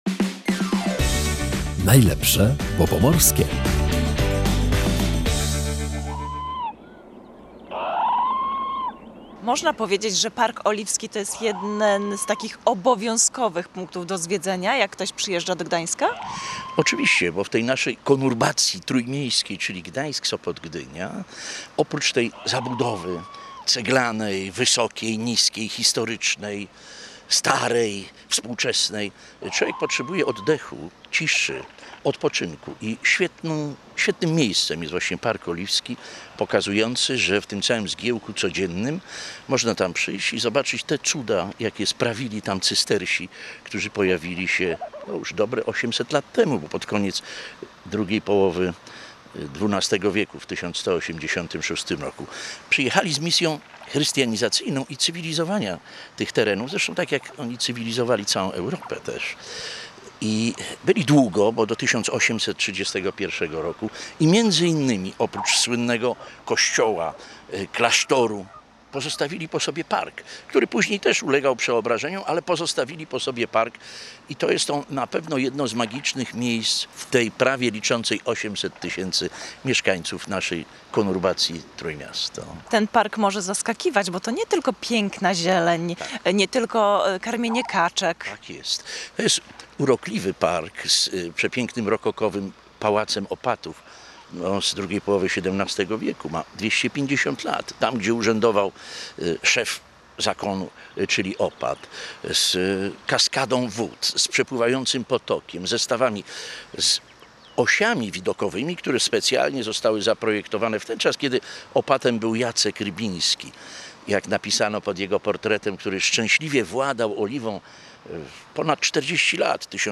Zapraszamy na spacer po ogrodzie japońskim w Parku Oliwskim